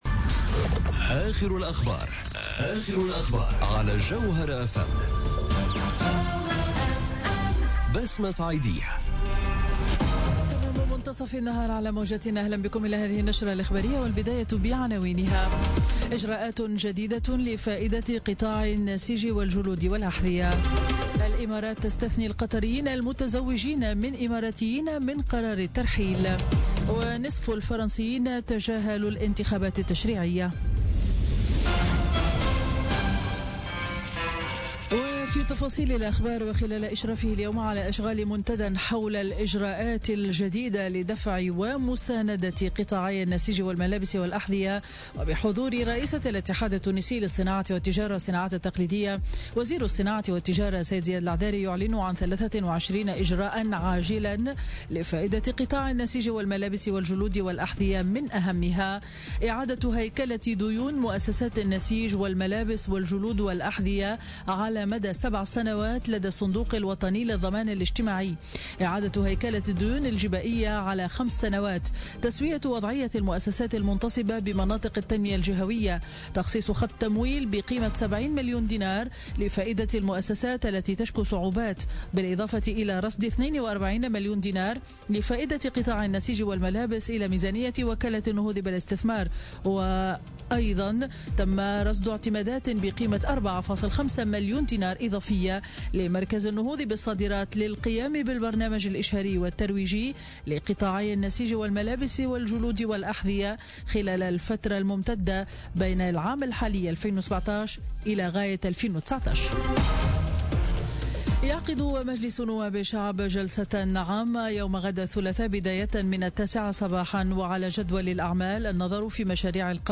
نشرة أخبار منتصف النهار ليوم الإثنين 12 جوان 2017